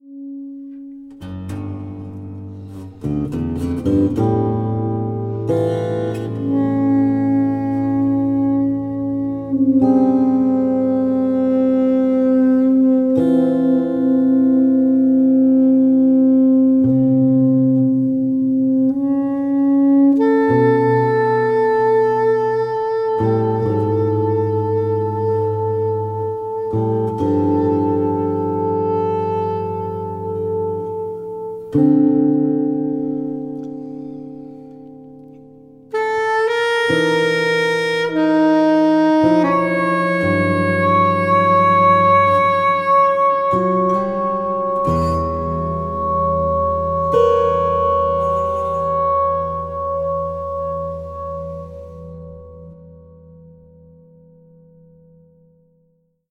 Tenor and Soprano saxophones, Alto flute, Bansuri flute